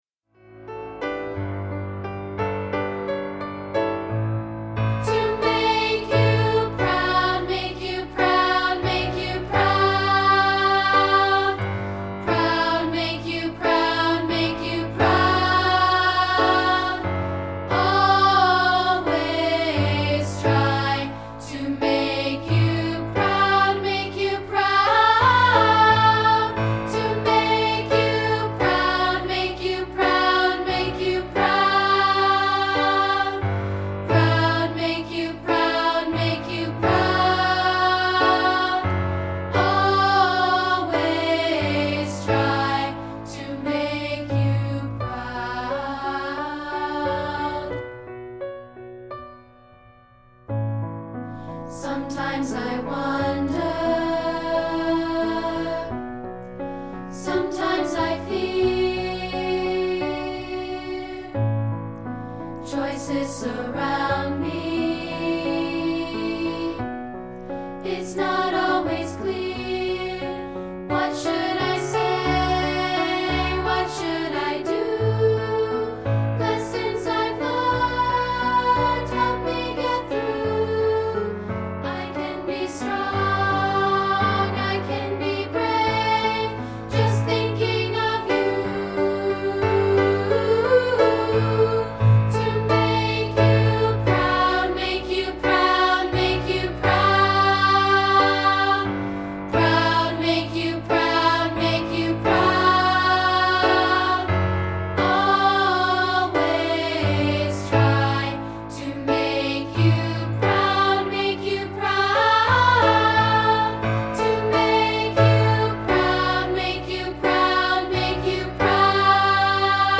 This rehearsal track features part 2 isolated.